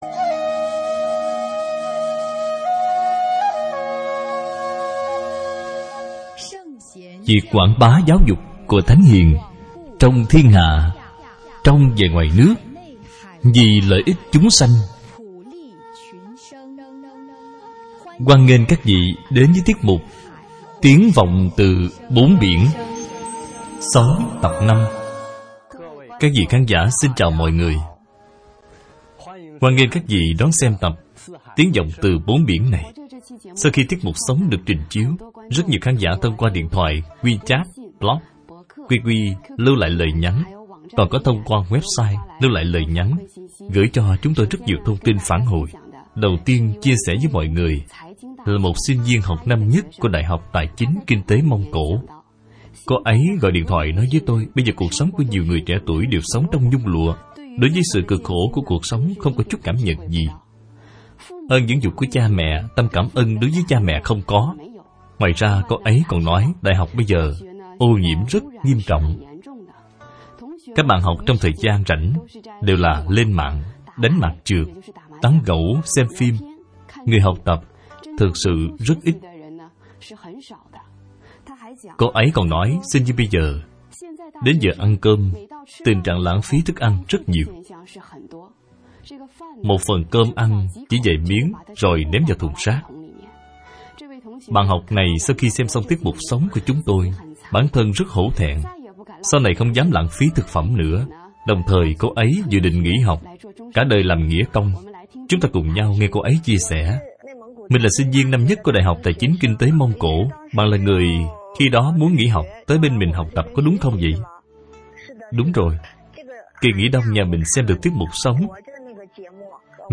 Sống - Tập 6 - Bài giảng Video